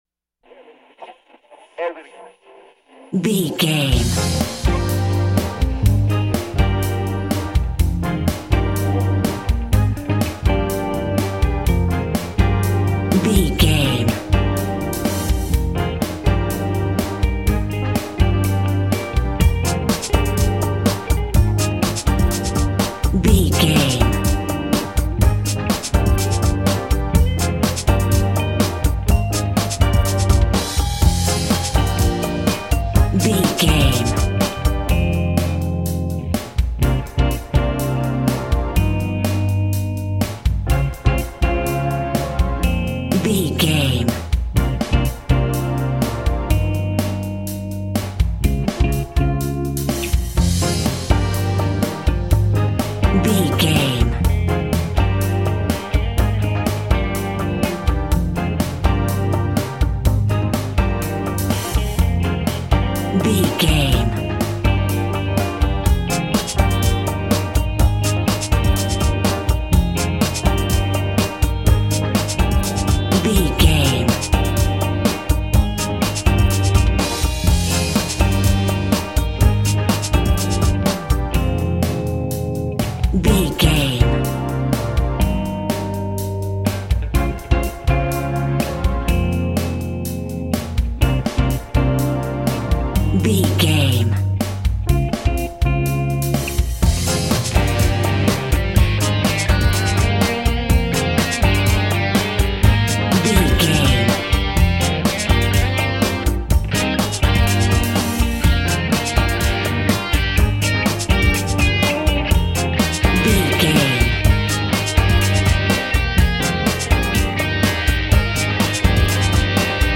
R+B Hip Hop Gospel Vibe.
Aeolian/Minor
Funk
soul
electronic
drum machine
synths